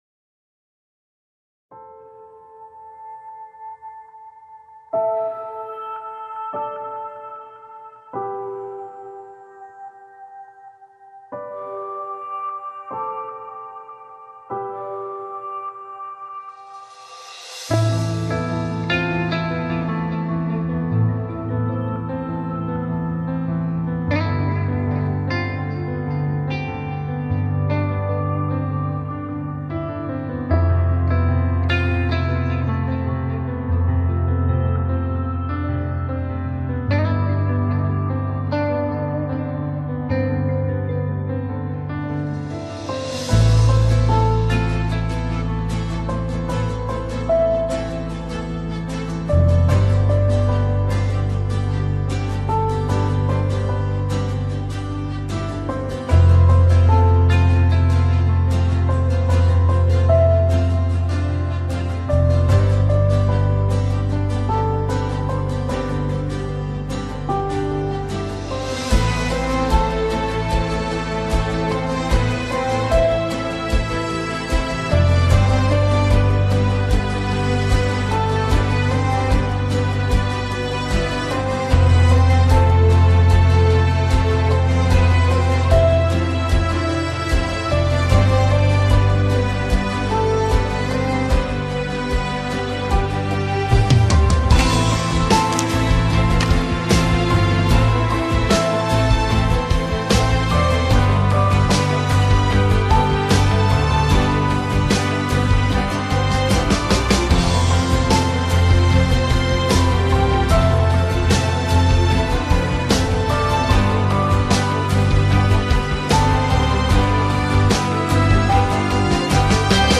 duygusal hüzünlü rahatlatıcı fon müziği.